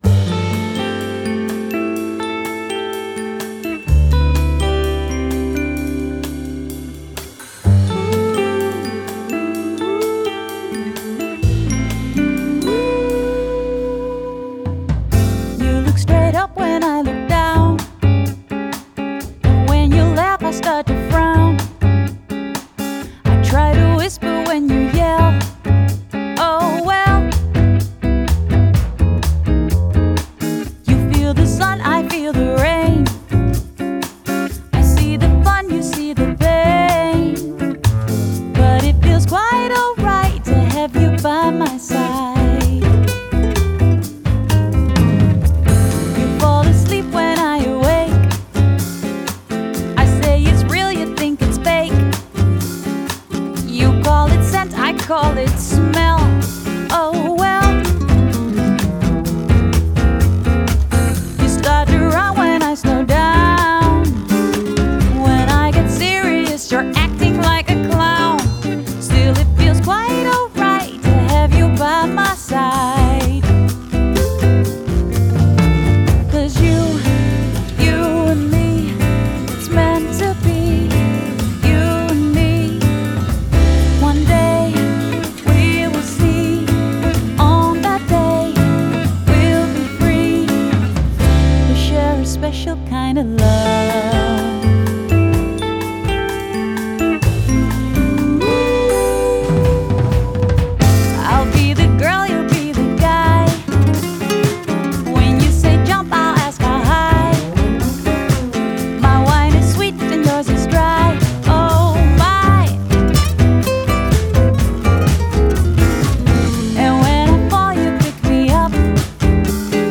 Genre: Jazz/Soul/Pop Vocals